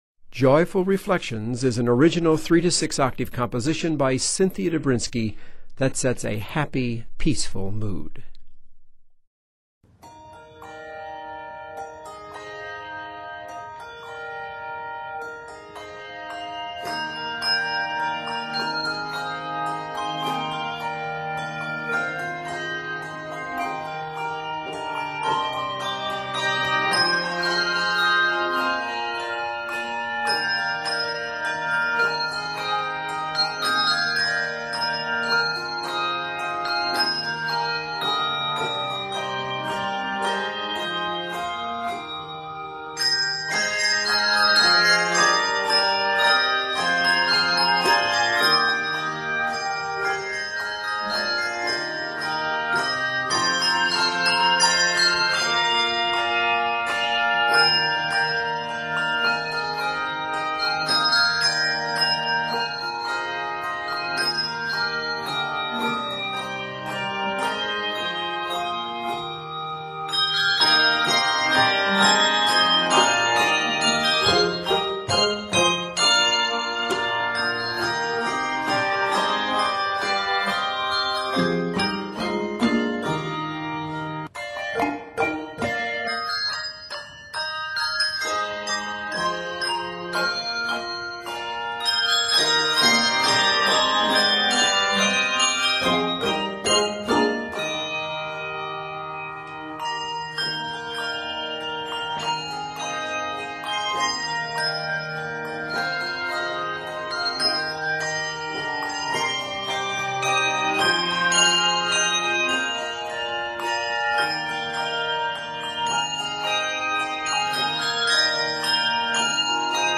handbells
Written in Eb Major, measures total 89.